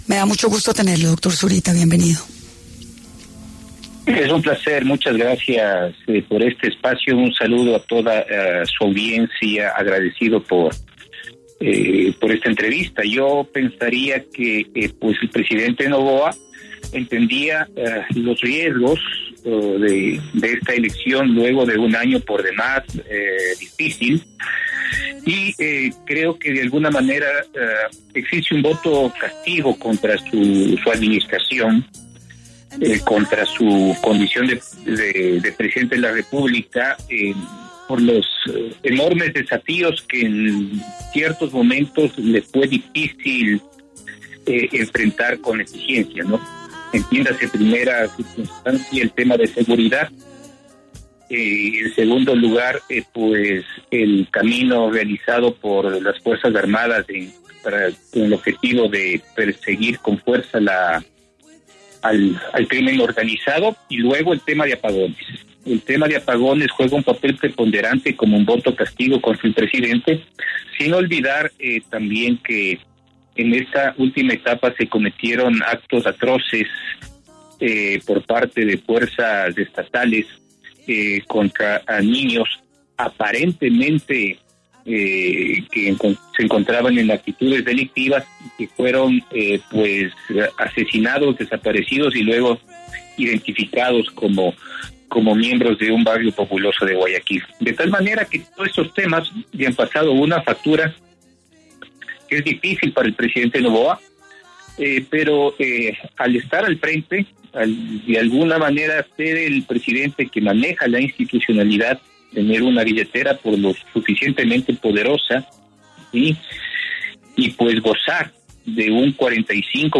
En 10AM de Caracol Radio estuvo Christian Zurita, periodista y excandidato presidencial de Ecuador. Habla de la segunda vuelta presidencial en Ecuador, que se realizará en abril.